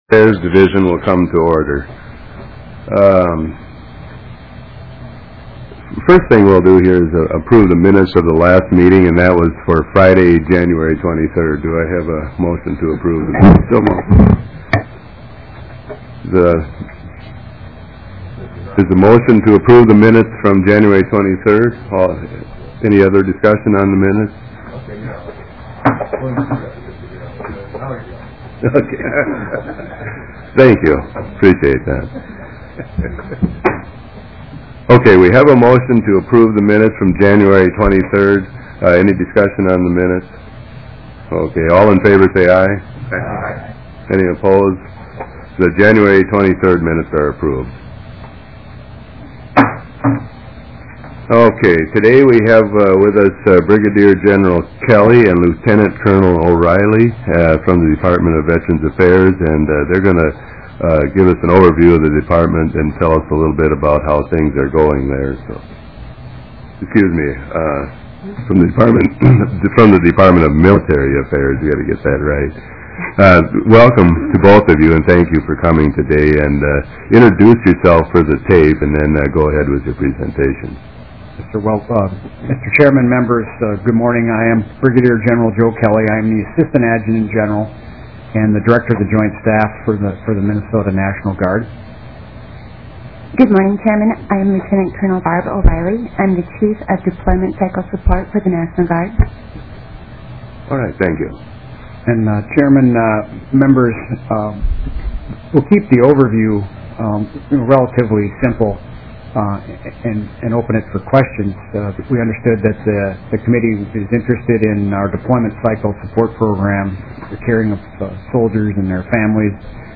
Representative Koenen, Chair of the Veteran Affairs Committee, called the first meeting to order at 8:35 AM on Friday, February 6,, 2009 in the Basement Hearing Room of the State Office Building.